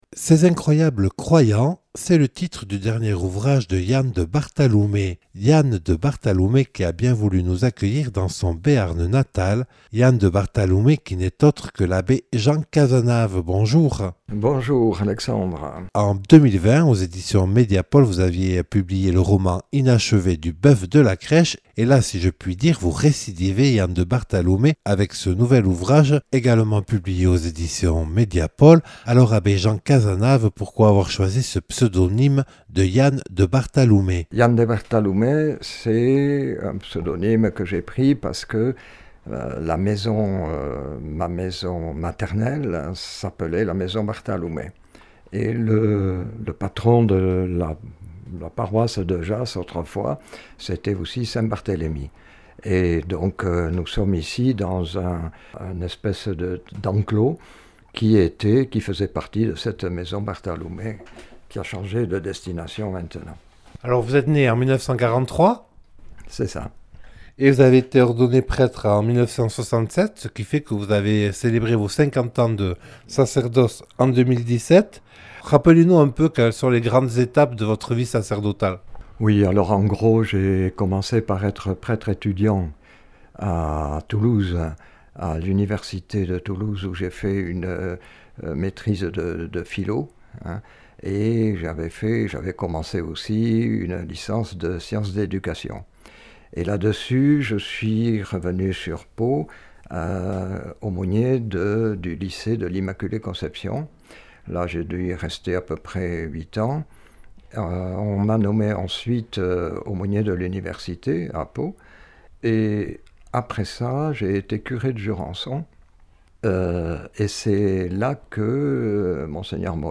Religion